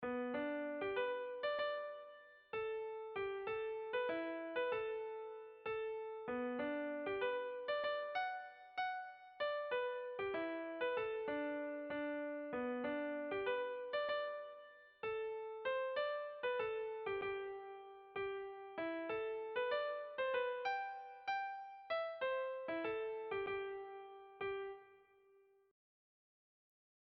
Sentimenduzkoa
Zortziko txikia (hg) / Lau puntuko txikia (ip)
A1A2A3A4